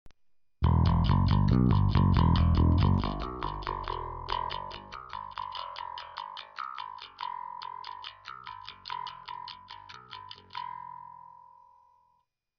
Ahoj, mám problém s nahráváním do pc přes Line6 Bass Pod XT, mám tu rackovou verzi, všechno propojim, dobry, normalně to hraje dobře ale když chci něco nahrát tak se postupně zhorší zvuk, totalne se ořežou basy apod... viz. nahrávka.